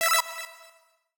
Universal UI SFX / Clicks
UIClick_Retro Delay 01.wav